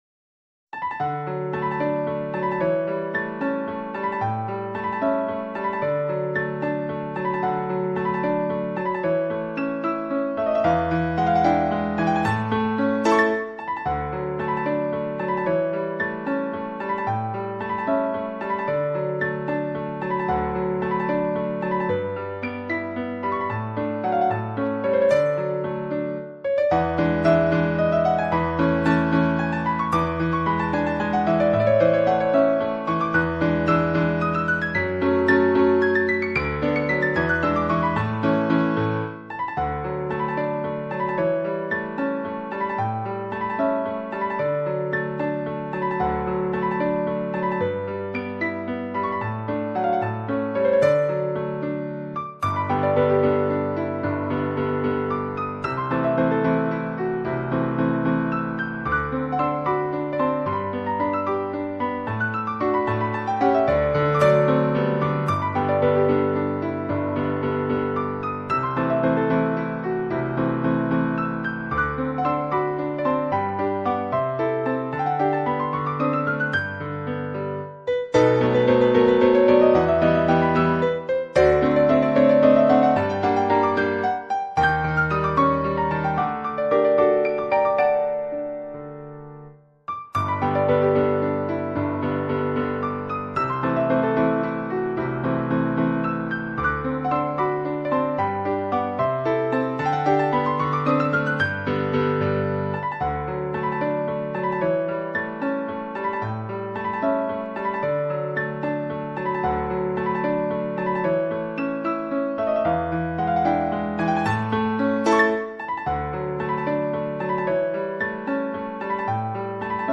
La base è uno spartito originale di fine Ottocento: “Diavolina”, Allegretto scherzando di Gustav Lange, Op. 340. Una composizione leggera e brillante, dal carattere ironico e giocoso, che diventa qui terreno di contrasto.